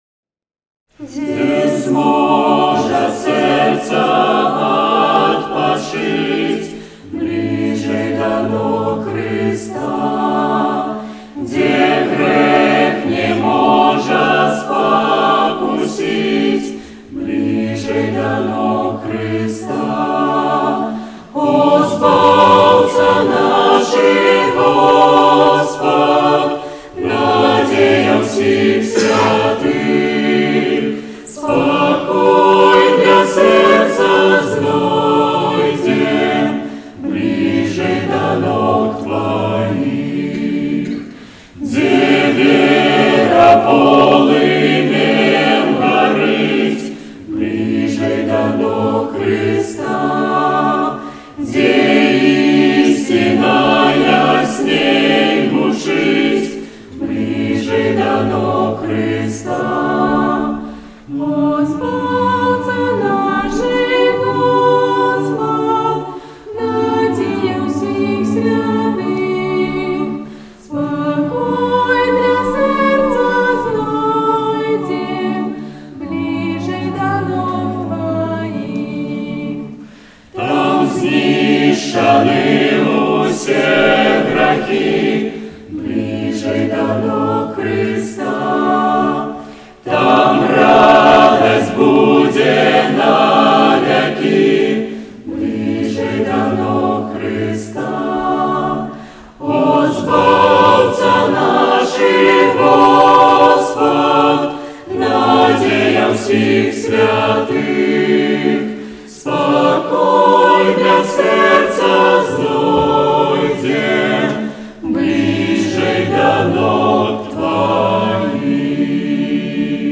19-11-17 / Блiжэй да ног Хрыста (Молодёжное прославление)